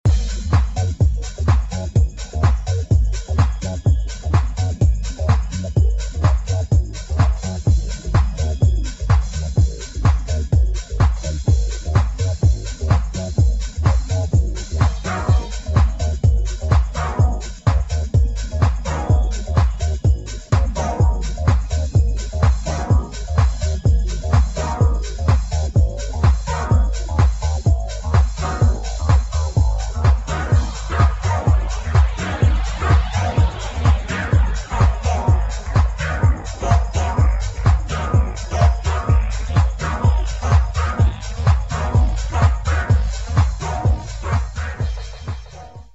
HOUSE | DISCO